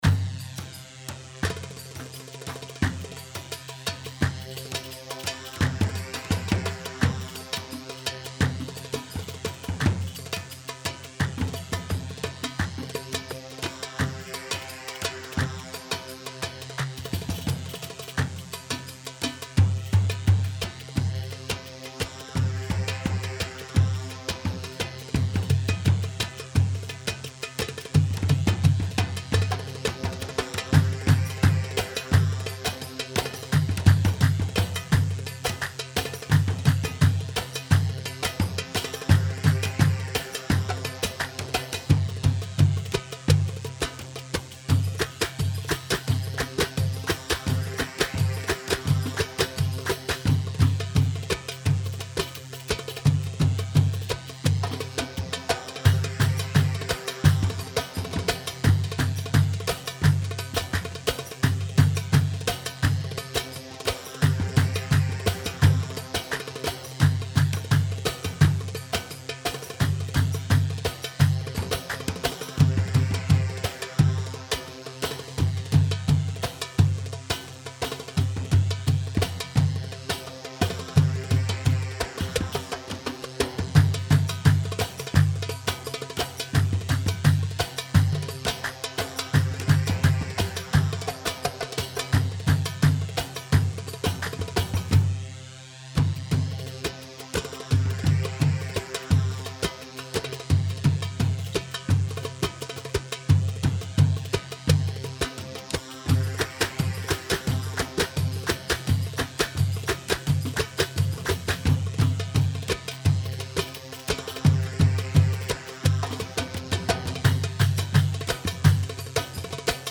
Iraqi
Chobi 4/4 86 شوبي